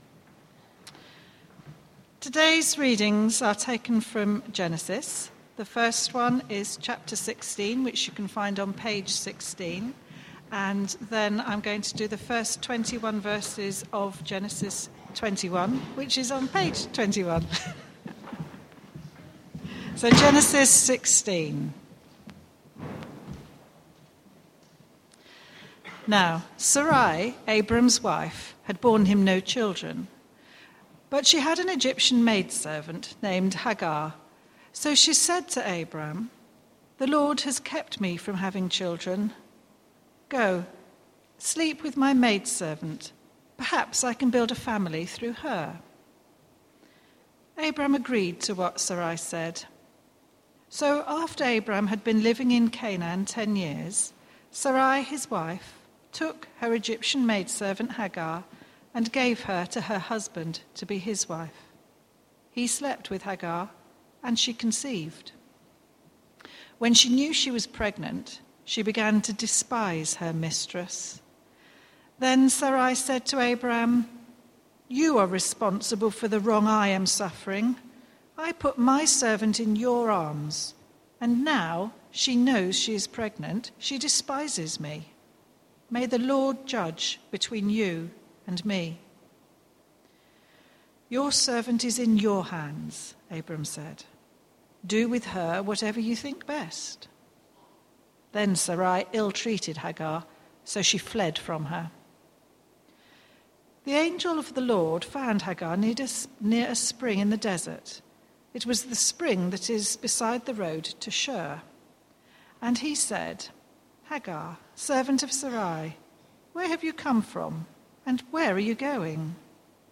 Media for Christ Church Morning Service on Sun 24th Mar 2013 10:30
Passage: Genesis 16,21 Series: From small beginnings Theme: Sermon